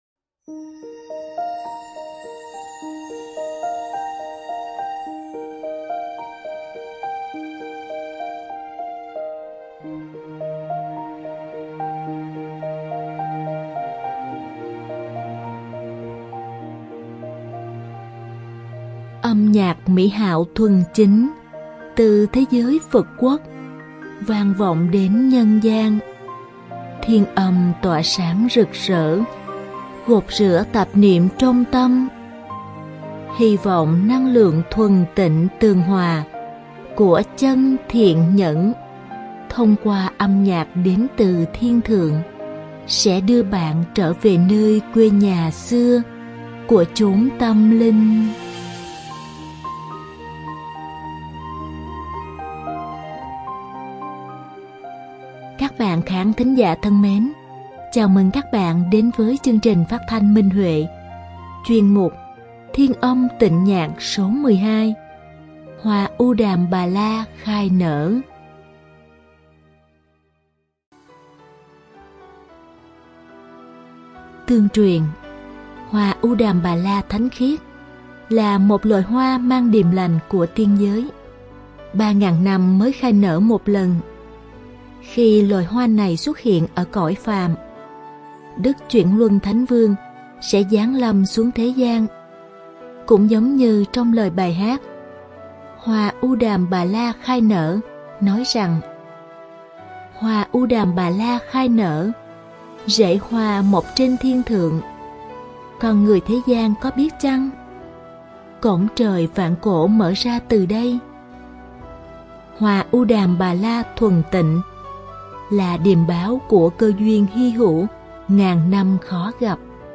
Đơn ca nữ
Diễn tấu cổ tranh